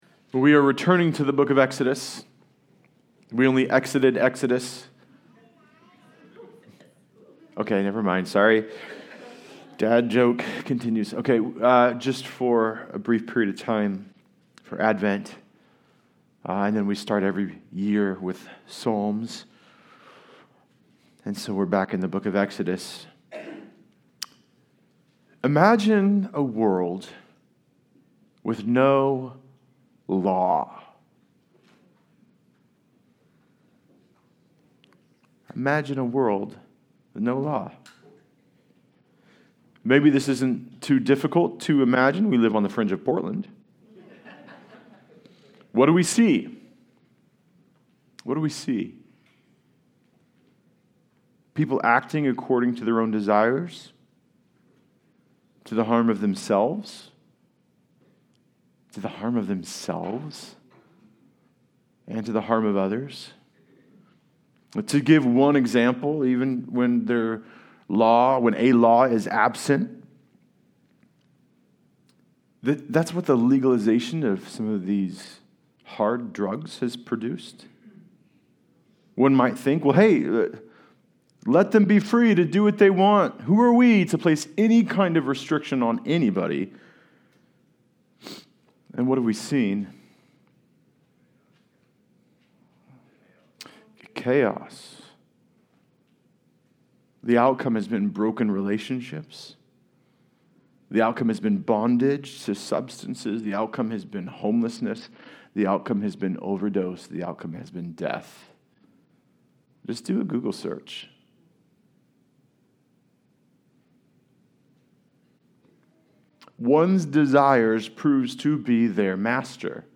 23:9 Service Type: Sunday Service Related « Fear Is the Shadow of What You Love Work